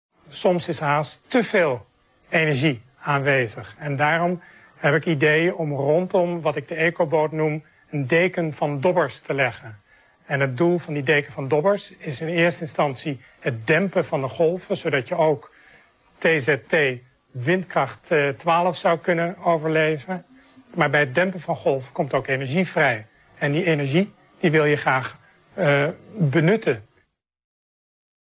gesproken commentaar